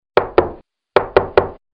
Index of /phonetones/unzipped/LG/KU5500/Message tones
Knock.mp3